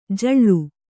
♪ jaḷḷu